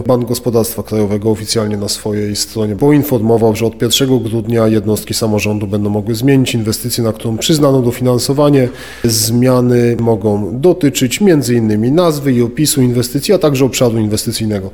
O tym, że jest taka szansa poinformował wiceprezydent Mateusz Tyczyński na ostatniej Sesji rady Miejskiej: